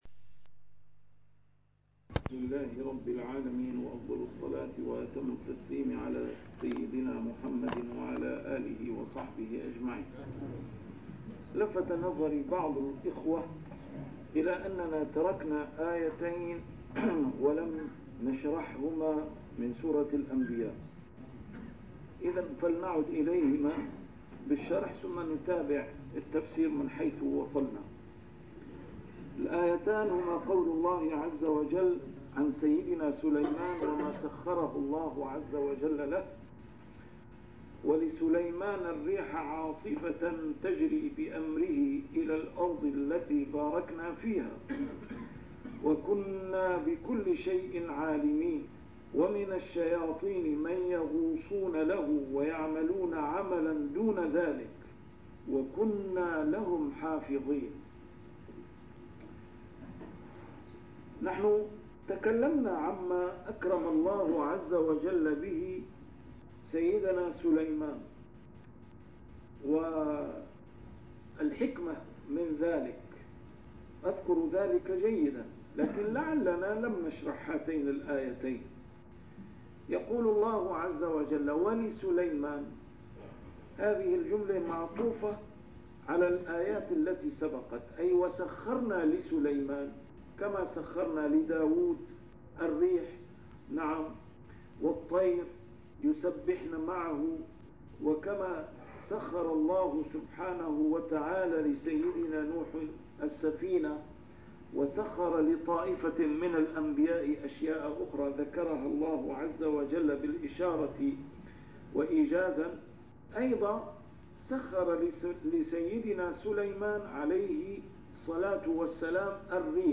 A MARTYR SCHOLAR: IMAM MUHAMMAD SAEED RAMADAN AL-BOUTI - الدروس العلمية - تفسير القرآن الكريم - تسجيل قديم - الدرس 99: الأنبياء 081-082